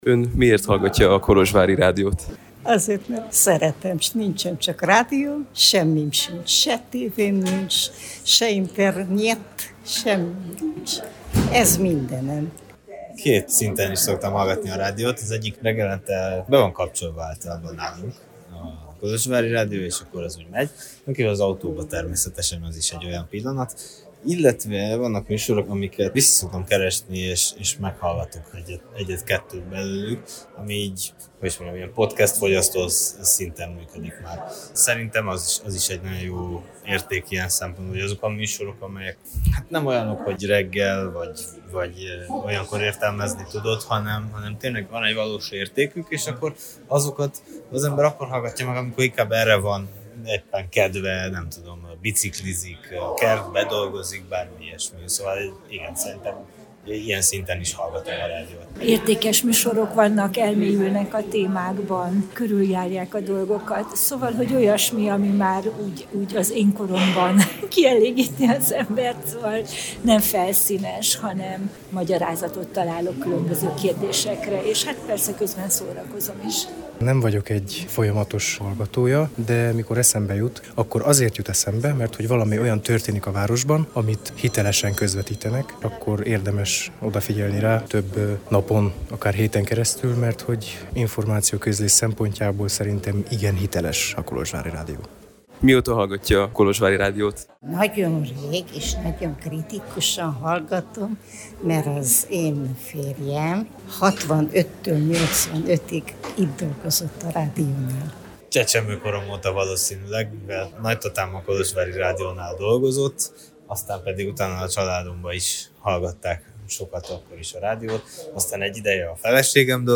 A filmvetítés előtt az érdeklődőket arról kérdeztük, hogy miért hallgatják a Kolozsvári Rádiót.